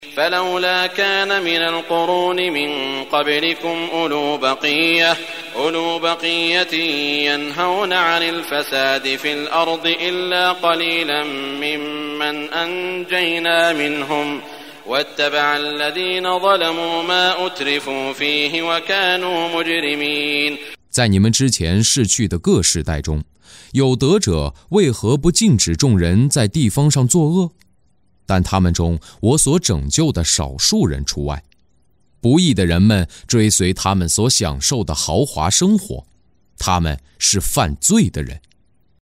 中文语音诵读的《古兰经》第（呼德章）章经文译解（按节分段），并附有诵经家沙特·舒拉伊姆的诵读